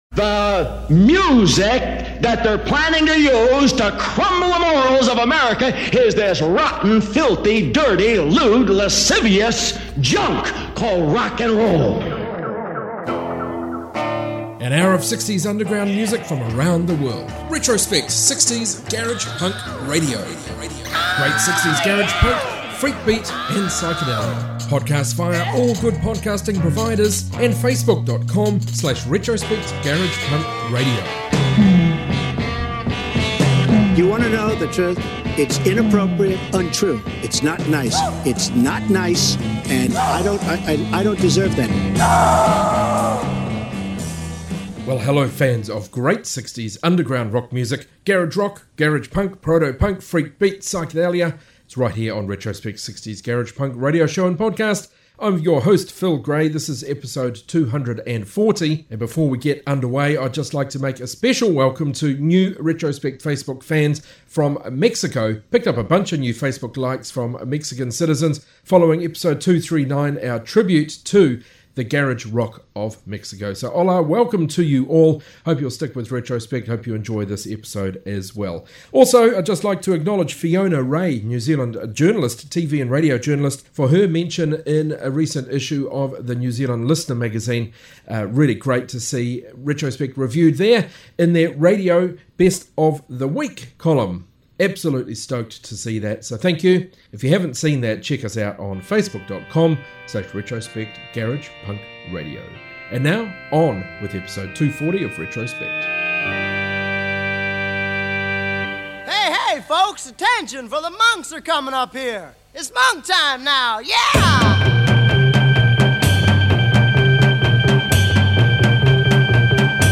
60s Garage Rock, Garage Punk, Proto-Punk, Freakbeat, Psychedelia